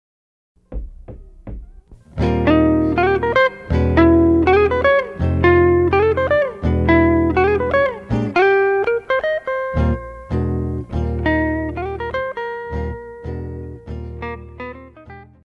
爵士
木吉他,古典吉他,電吉他
演奏曲
搖擺
二重奏
沒有主奏
沒有節拍器